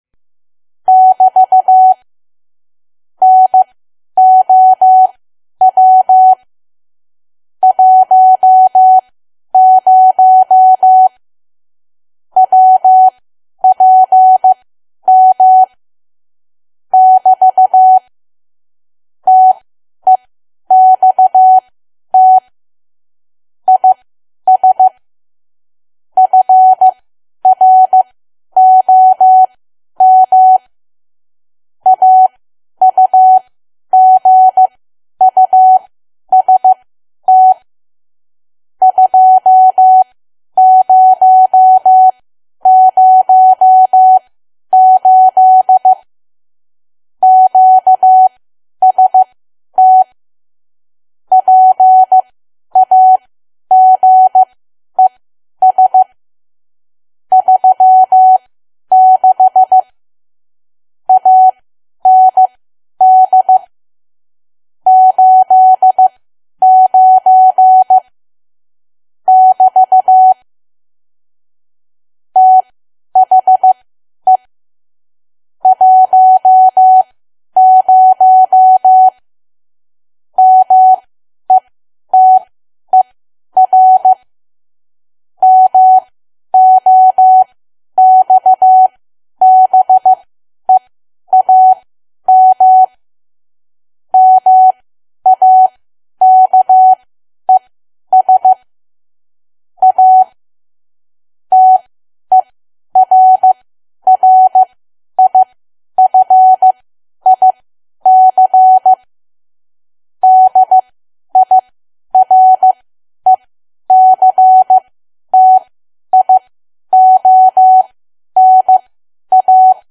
Learning Morse Code
Rather than using dots and dashes, it is best to learn the Morse code alphabet by the way it sounds: dits and dahs .